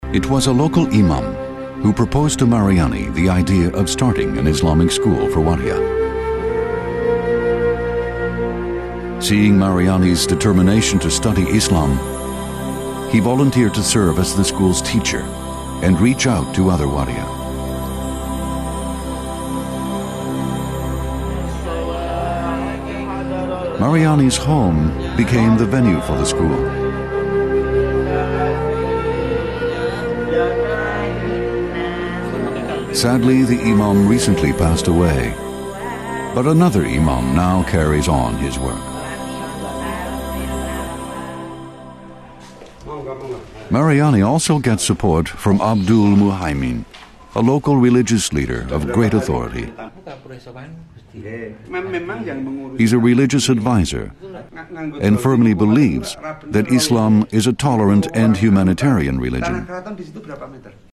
English inflection: Neutral North American
Tone: Baritone